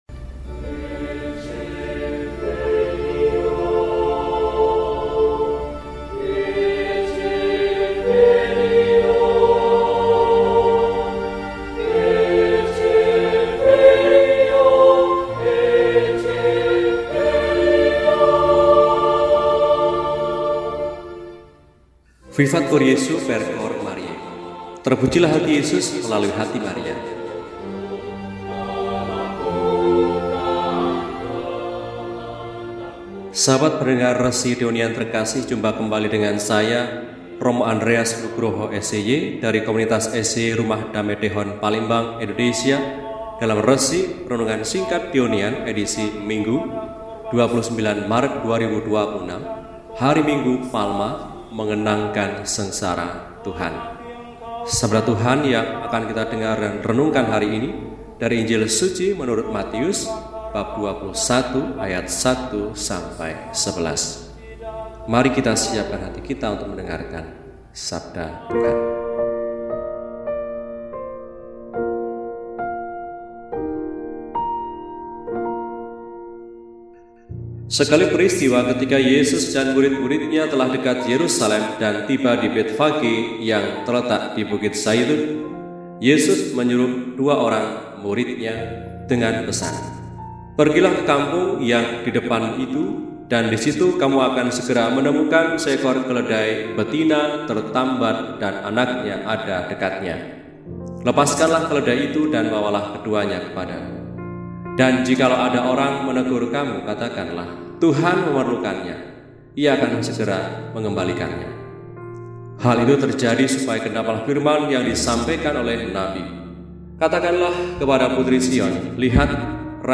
HOMILI SINGKAT